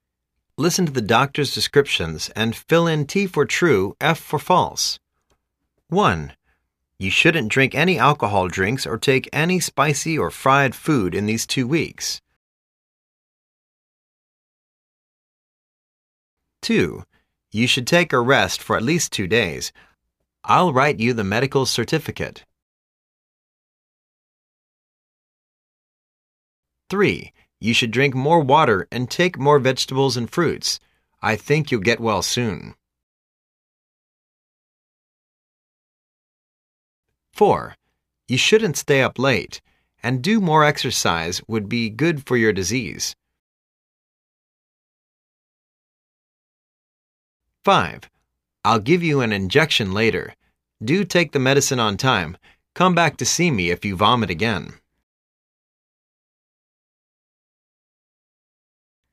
Listen to the doctor's descriptions and fill in T for "True",F for "False".